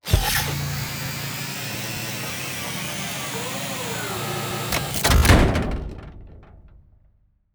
reload1.wav